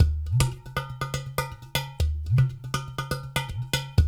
120 -UDU 0CR.wav